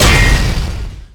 bladeslice4.ogg